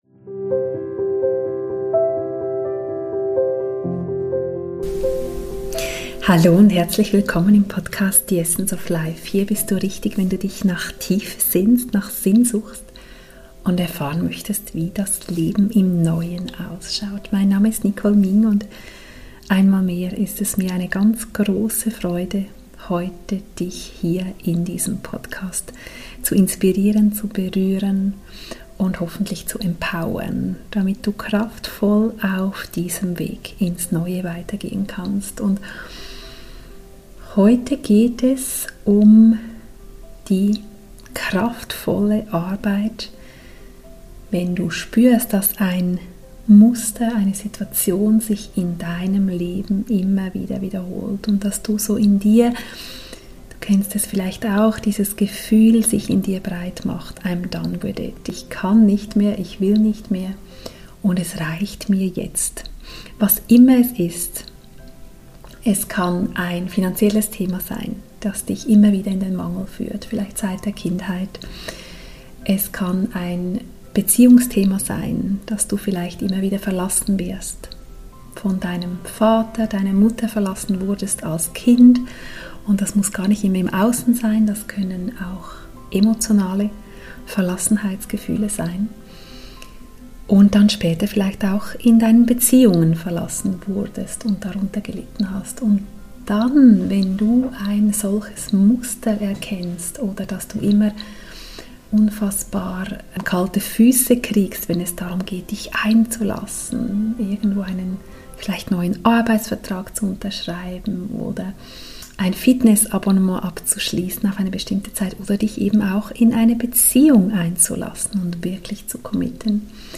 Dann bietet Dir diese Podcastfolge eine tiefe und sehr kraftvolle Meditation, um genau dies zu tun.